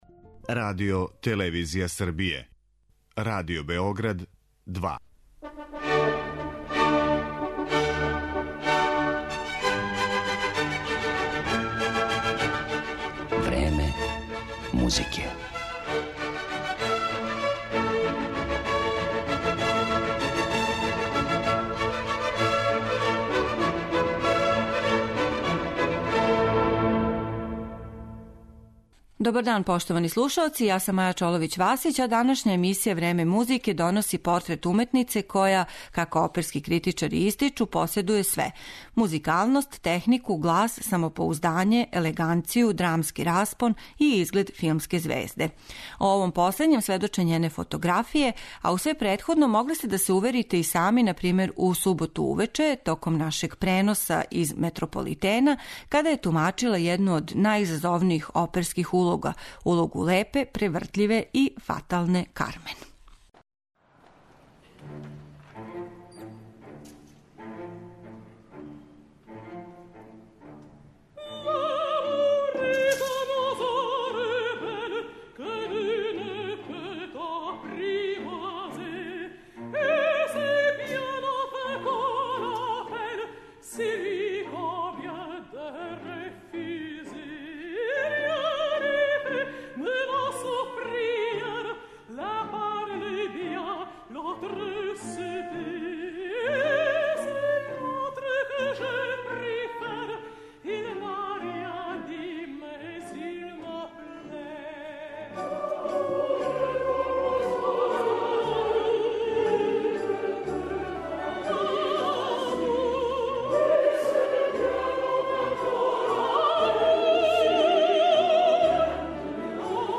летонски мецосопран